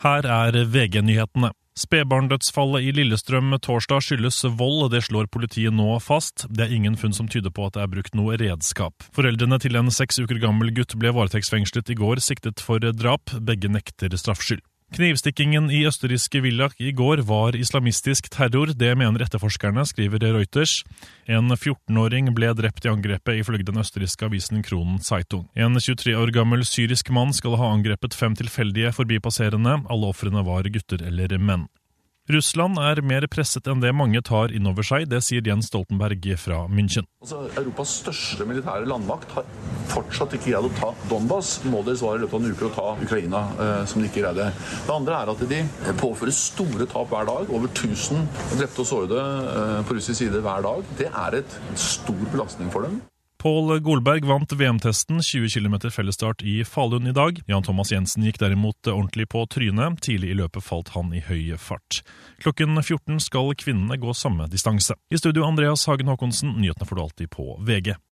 1 Siste nytt fra VG 1:08 Play Pause 1h ago 1:08 Play Pause Nghe Sau Nghe Sau Danh sách Thích Đã thích 1:08 Hold deg oppdatert med ferske nyhetsoppdateringer på lyd fra VG. Nyhetene leveres av Bauer Media/Radio Norge for VG.